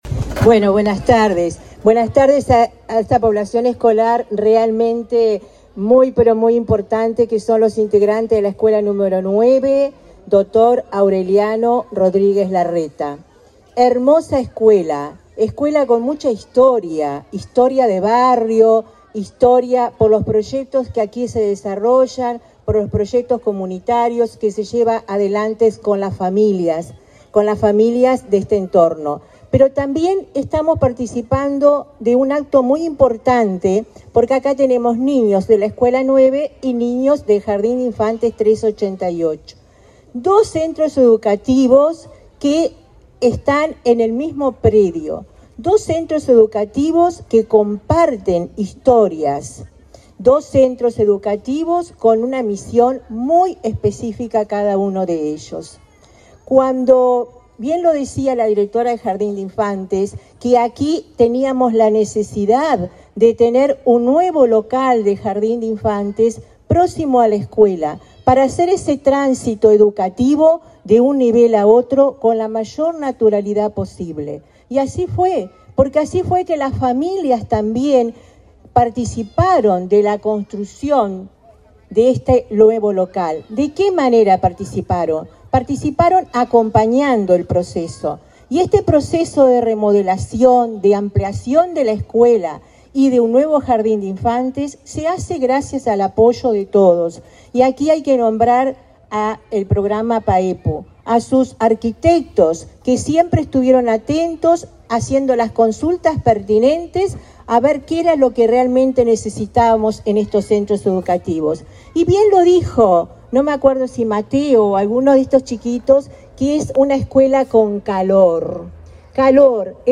Palabras de autoridades de la ANEP
Palabras de autoridades de la ANEP 20/06/2023 Compartir Facebook X Copiar enlace WhatsApp LinkedIn Este martes 20, la ANEP presentó en el barrio de la Teja de Montevideo, la ampliación y remodelación de la escuela n.º 9 e inauguró el jardín de infantes n.º 388, ubicado en el mismo predio. La directora interina de Primaria, Olga de las Heras, y el presidente de la institución, Robert Silva, destacaron la importancia de esta infraestructura.